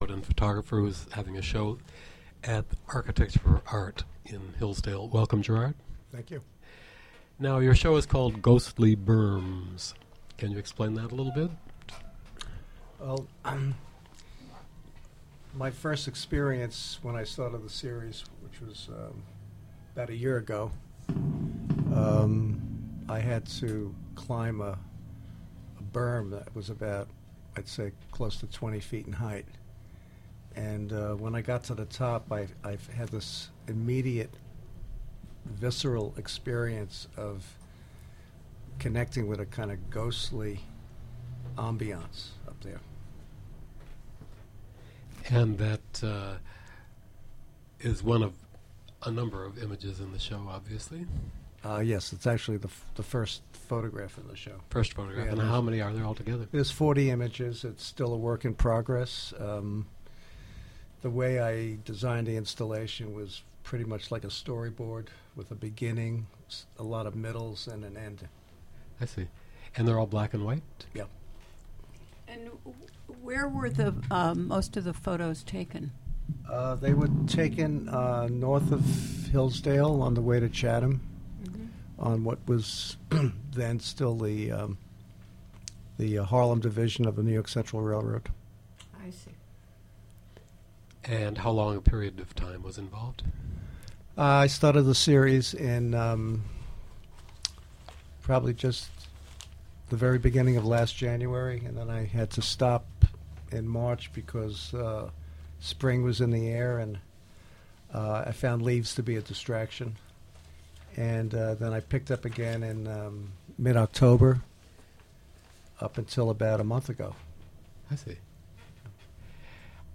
Interview on "WGXC Afternoon Show."